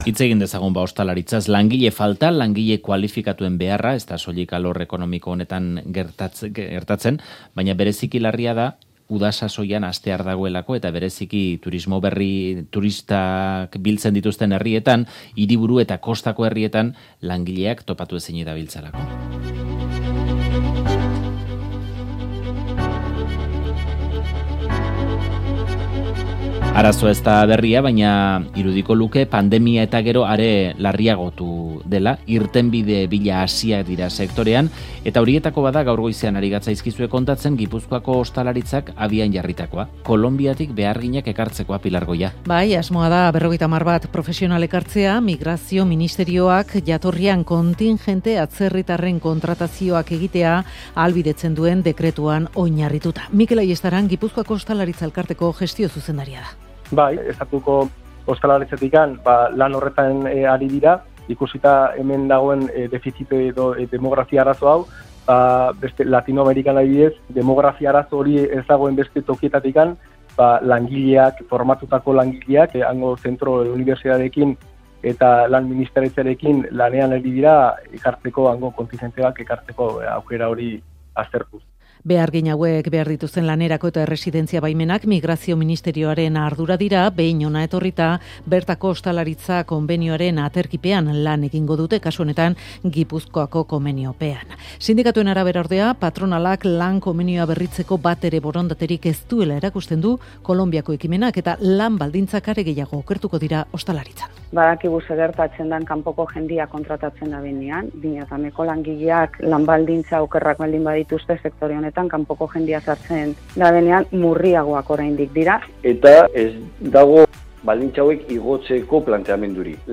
Ostalaritza sektoreko hainbat elkarte irtenbide bila hasi dira, tartean Gipuzkoakoa. Sektoreak bizi duen langile faltaz mintzatu gara FAKTORIAn, zerbitzari zein enpresarioekin.